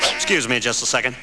He's big. He's strong. He talks like Elvis and thinks he's god's gift to women... What's not to like?